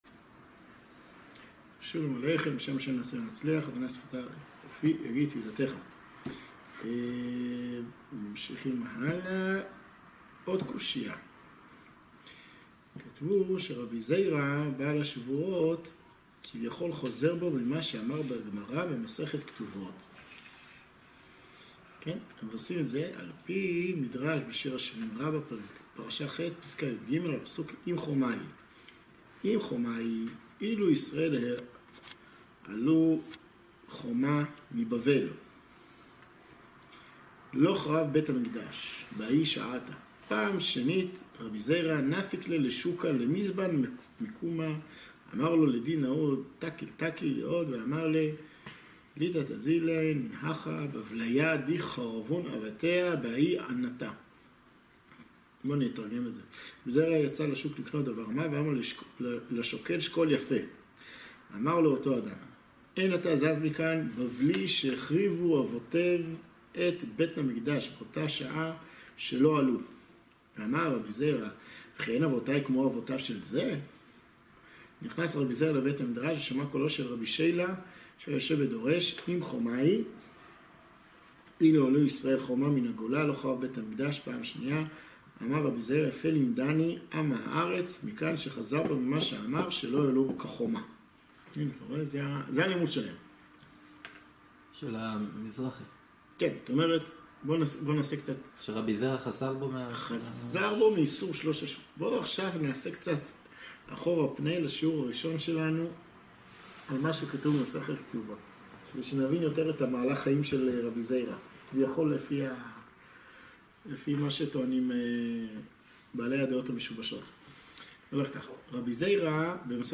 שיעור עשירי - האם רבי זירא חזר בו